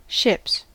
Ääntäminen
Ääntäminen US Tuntematon aksentti: IPA : /ʃɪps/ Haettu sana löytyi näillä lähdekielillä: englanti Ships on sanan ship monikko.